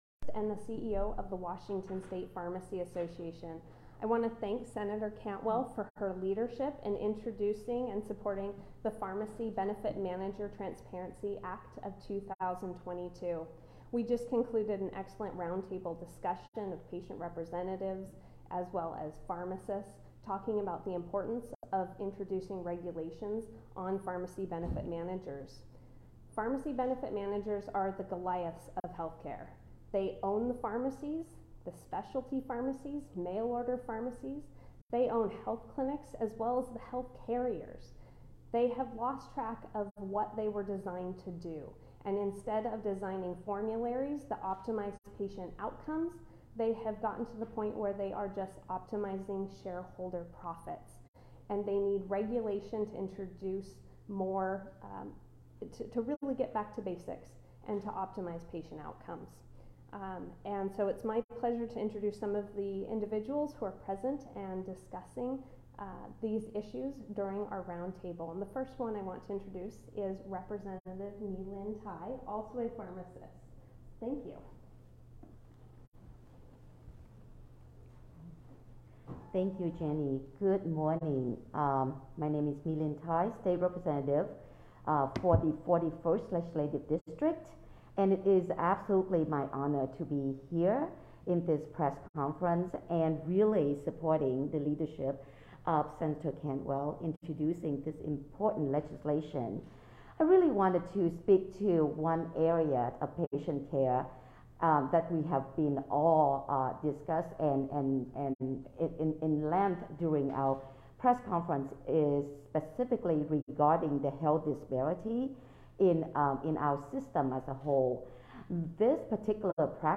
SEATTLE, WA – Today, U.S. Sen. Maria Cantwell (D-WA) and U.S. Rep. Kim Schrier (D-WA-8) were joined by local pharmacists, industry stakeholders, and others affected by skyrocketing costs of medications in Seattle for a roundtable discussion and press conference to advocate for legislation that would lower prescription drug costs. Sen. Cantwell also highlighted her bipartisan bill that would hold pharmacy benefit managers (PBMs) accountable for their unfair and deceptive practices.